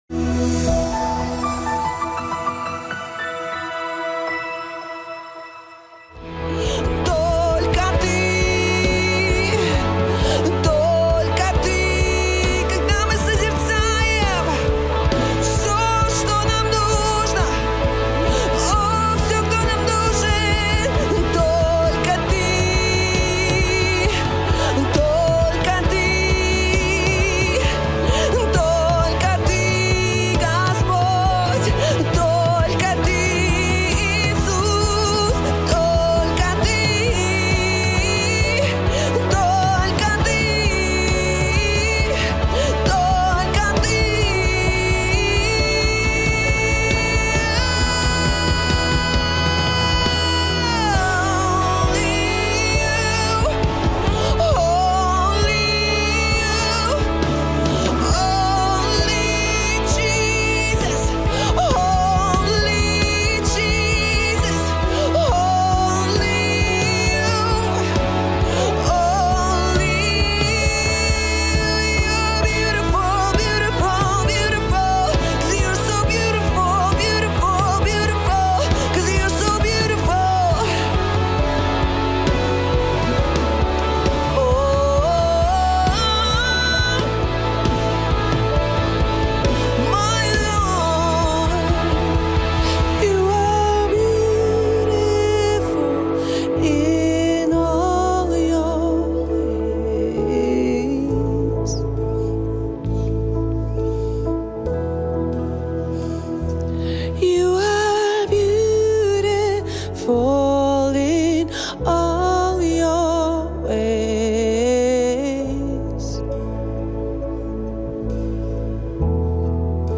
Molitva_850.mp3